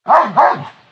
growl2.ogg